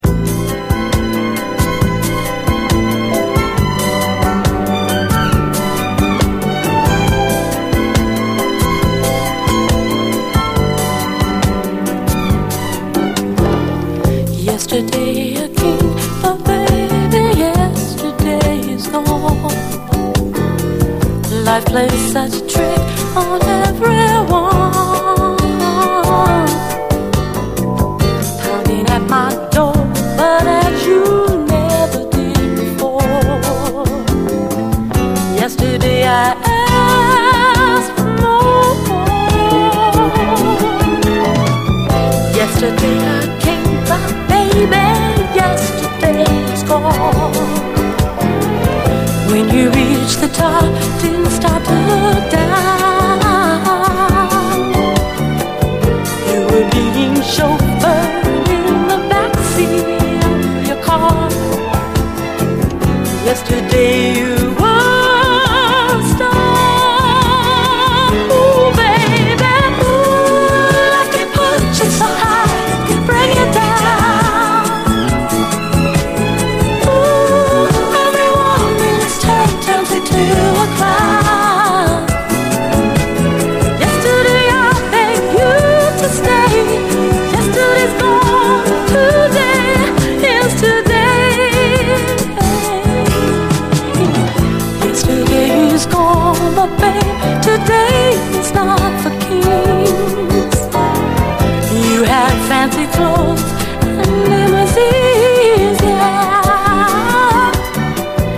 SOUL, 70's～ SOUL
ストリングスが舞う、切なくも美しいユーロ産美麗ソウル！